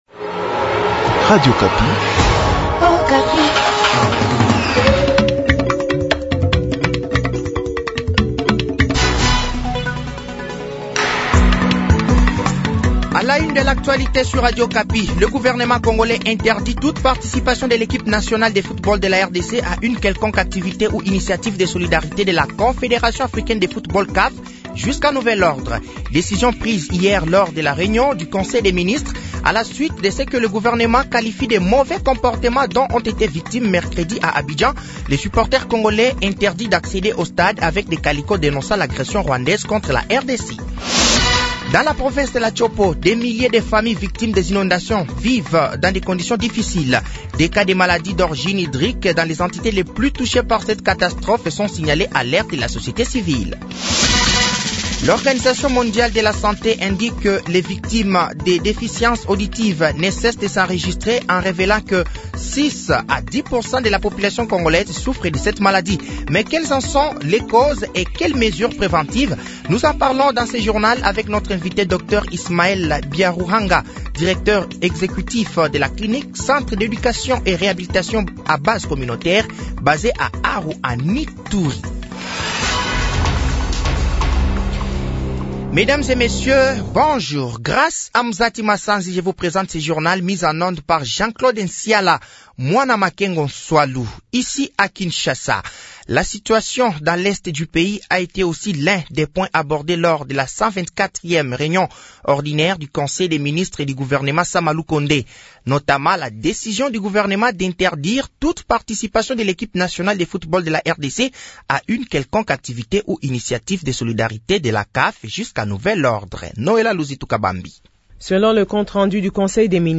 Journal français de 7h de ce samedi 10 février 2024